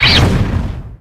Audio / SE / Cries / LARVESTA.ogg